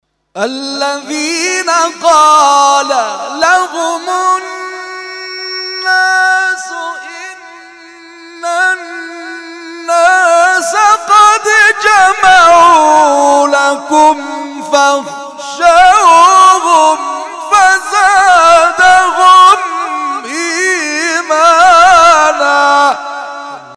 محفل انس با قرآن در آستان عبدالعظیم(ع) + صوت